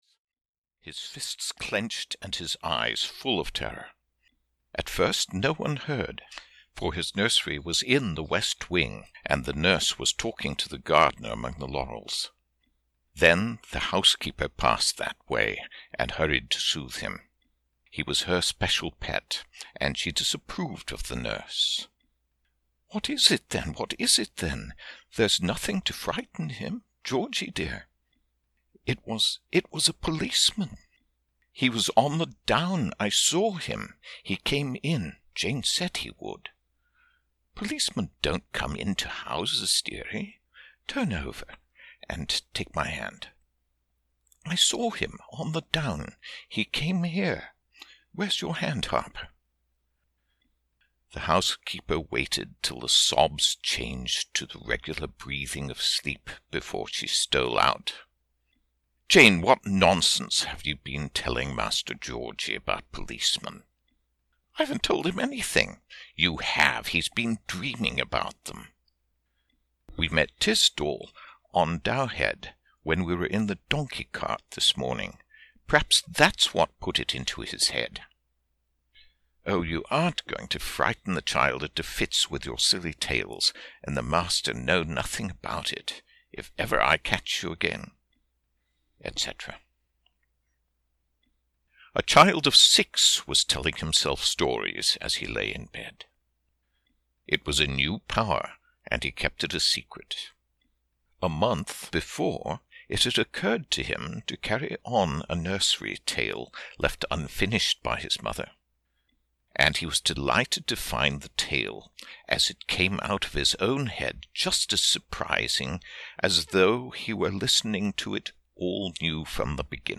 The Brushwood Boy (EN) audiokniha
Ukázka z knihy